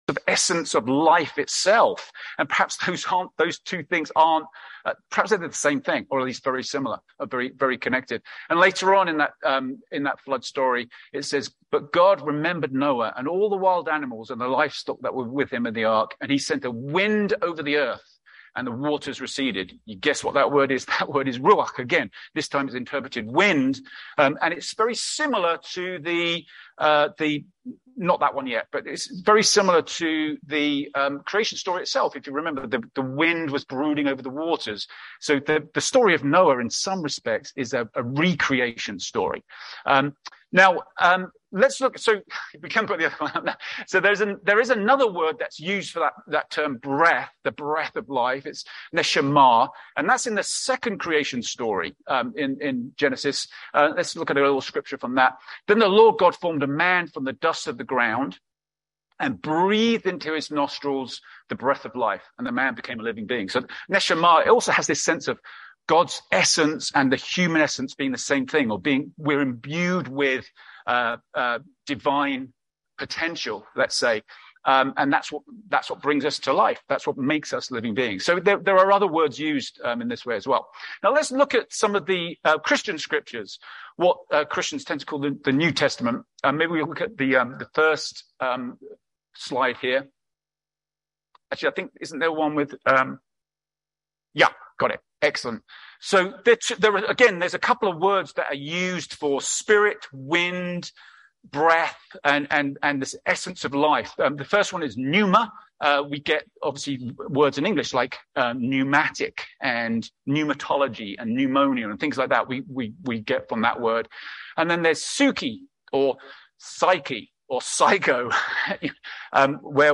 A message from the series "Elemental Spirituality." This week we talked about how God can be seen in the wind, and the very air we breathe.